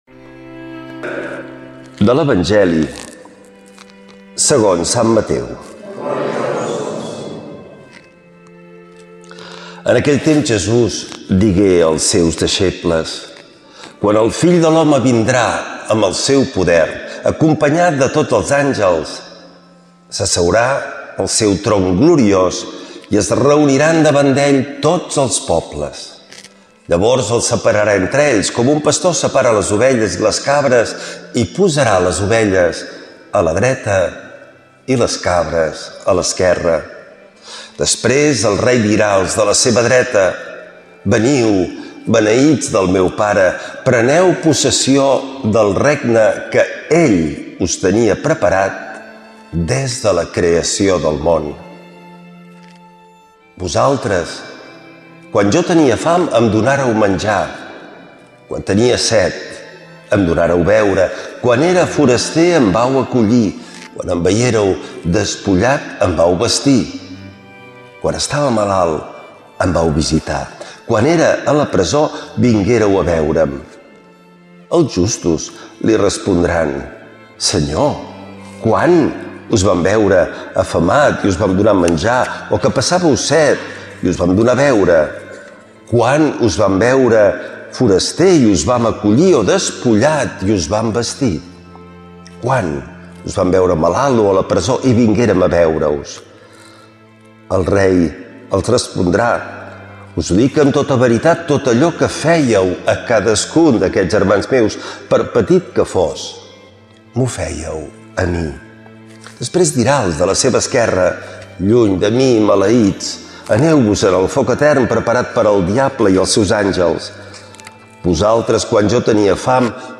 L’Evangeli i el comentari de diumenge 02 de novembre del 2025.